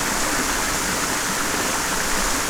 waterloop.wav